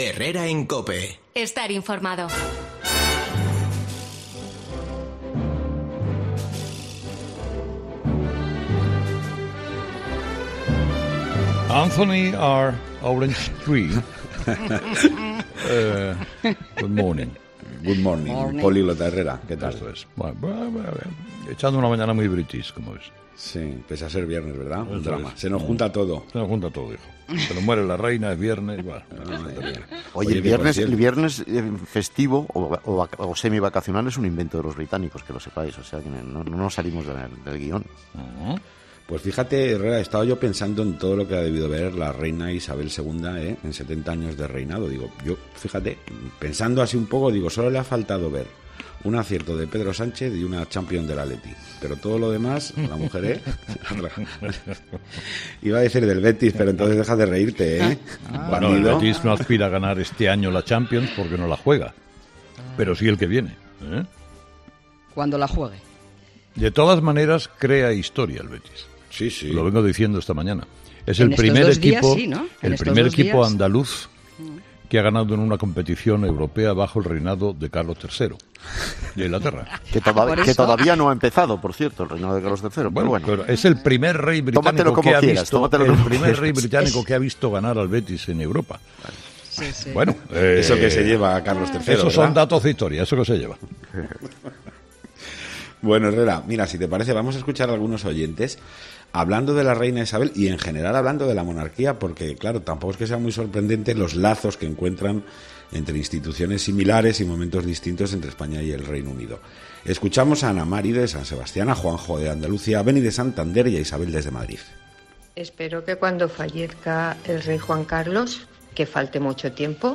AUDIO: Los oyentes, de nuevo, protagonistas en 'Herrera en COPE' con su particular tertulia.